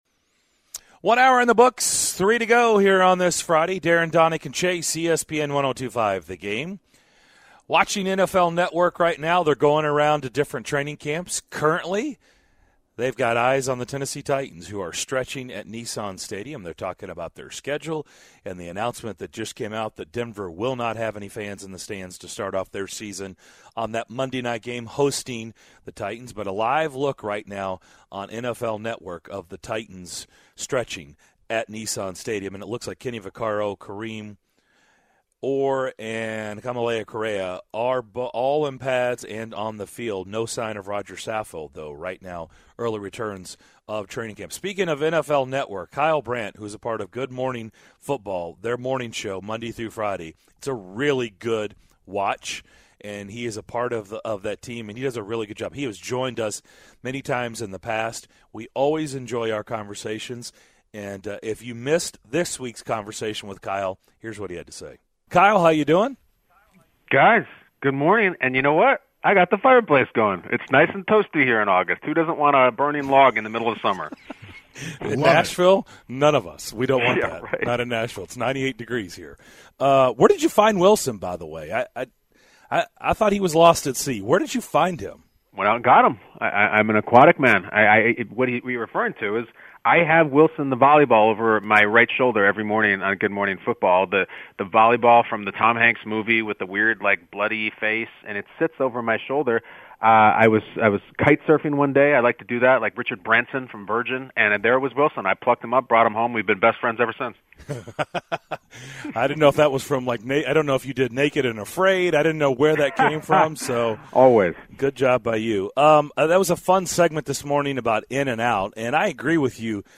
We reprise our interview with Good Morning Football co-host Kyle Brandt as originally heard on Tuesday. -22:00, What will COVID guidelines look like at football games? -38:00, Where does Mike Vrabel rank amongst NFL coaches?